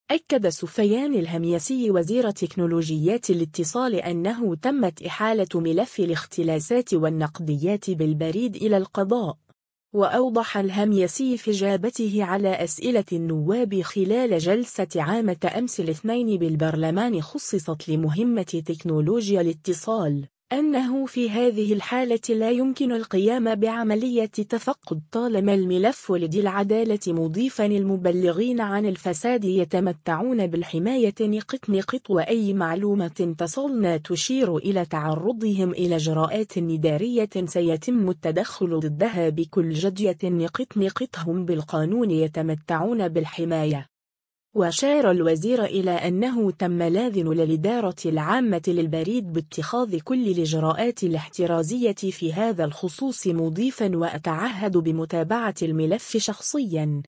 وأوضح الهميسي في اجابته على أسئلة النواب خلال جلسة عامة أمس الاثنين بالبرلمان خصصت لمهمة تكنولوجيا الاتصال، أنه في هذه الحالة لا يمكن القيام بعملية تفقد طالما الملف لدى العدالة مضيفا “المبلغين عن الفساد يتمتعون بالحماية..وأي معلومة تصلنا تشير الى تعرضهم الى اجراءات ادارية سيتم التدخل ضدها بكل جدية..هم بالقانون يتمتعون بالحماية”.